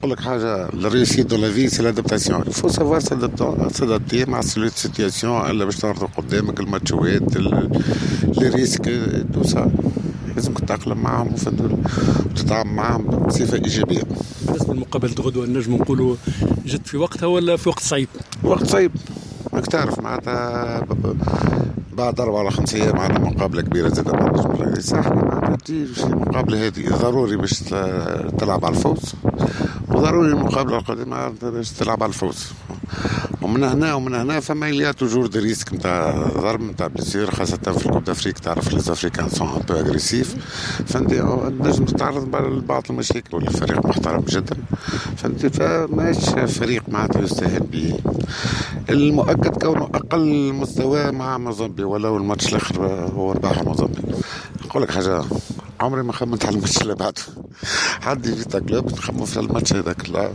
تحدث مدرب الترجي الرياضي التونسي فوزي البنزرتي حول مقابلة فريقه يوم غد إنطلاقا من الساعة 16:00 أمام فيتا كلوب الكونغولي لحساب الجولة الأولى من دور المجموعات.